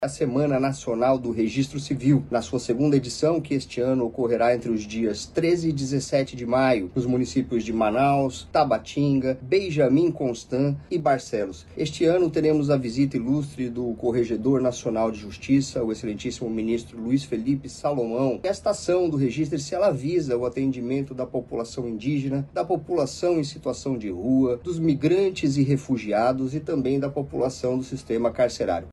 O juiz Rafael Cró, da Corregedoria do Tribunal de Justiça do Amazonas, explica que a ação tem como foco os grupos em situação de vulnerabilidade social.